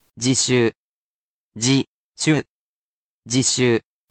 jishuu